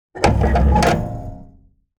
Lawn Mower, Lever Movement Sound Effect Download | Gfx Sounds
Lawn-mower-lever-movement.mp3